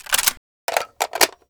Reloading_begin0009.ogg